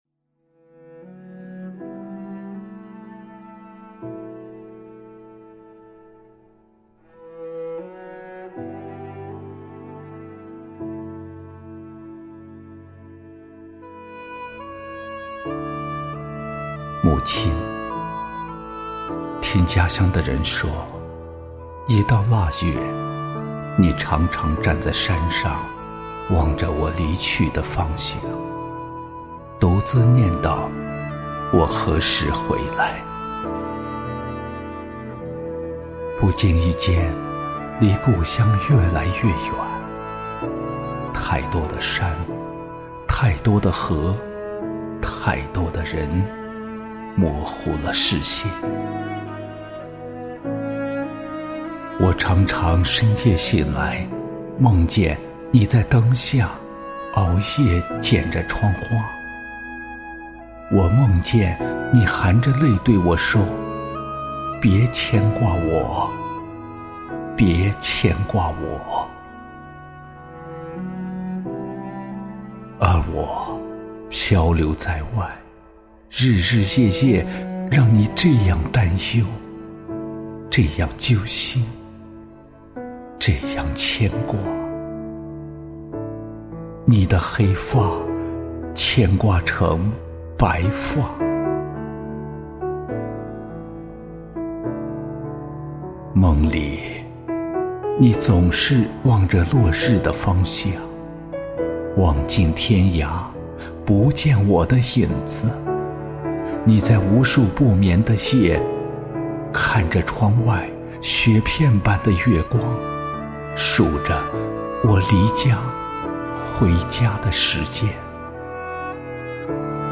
朗诵